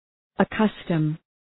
Προφορά
{ə’kʌstəm}